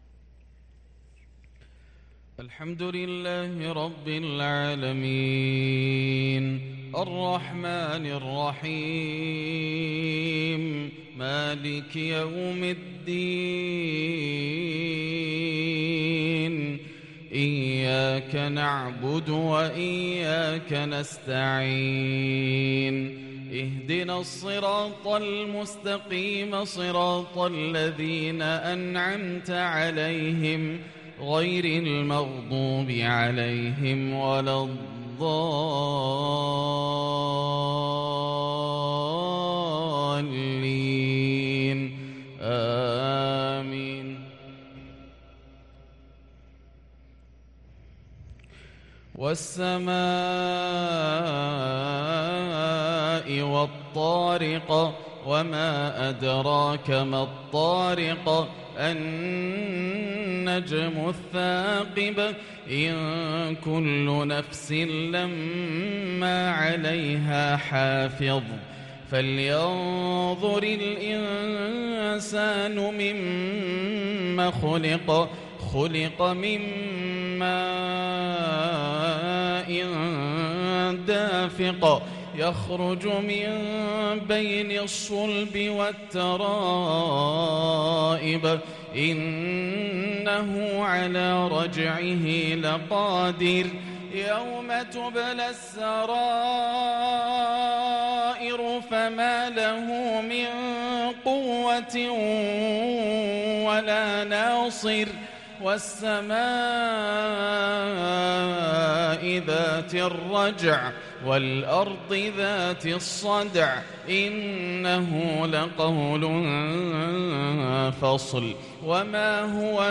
مغرب الجمعة 6-2-1444هـ سورتي الطارق و الكوثر | Maghrib prayer Surat At-Tariq and Al-Kawthar 2-9-2022 > 1444 🕋 > الفروض - تلاوات الحرمين